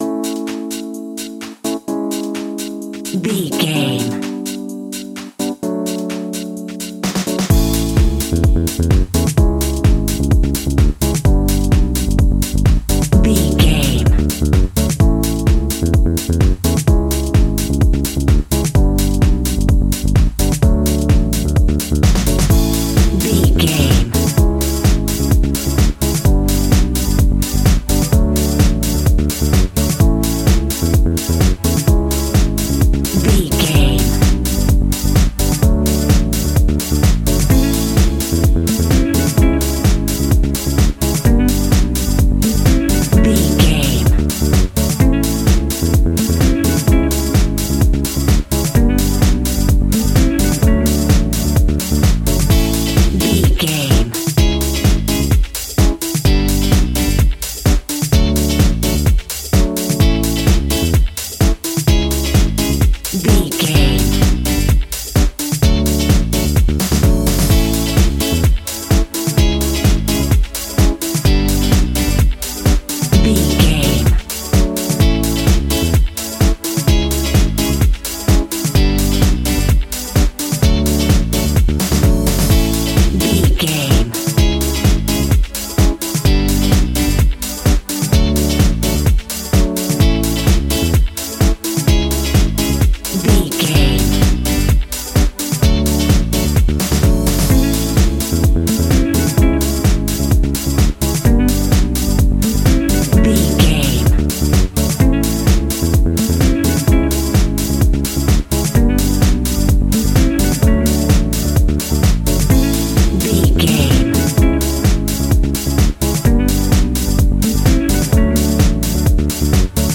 Ionian/Major
groovy
uplifting
bouncy
electric guitar
horns
drums
bass guitar
saxophone
disco
upbeat
clavinet
synth bass